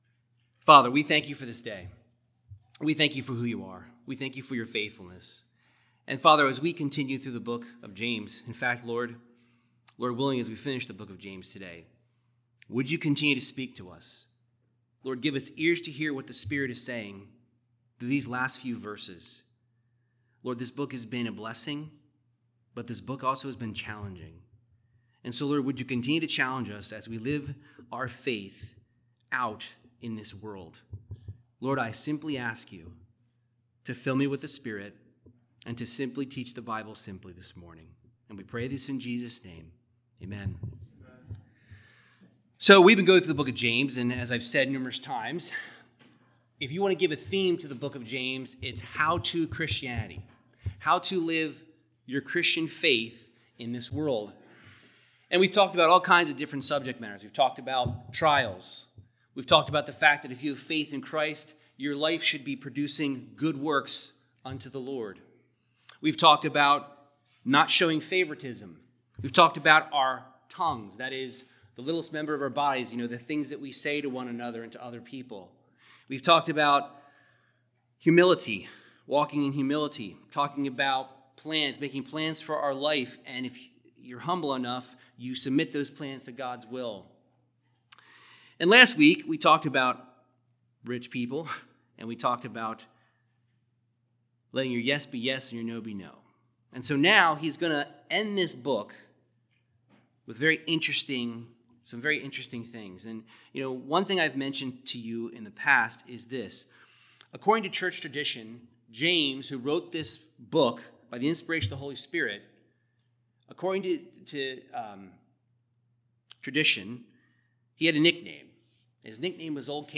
Verse by verse Bible teaching of James 5:13-20 discussing how the Christian community can care for each other through prayer and accountability.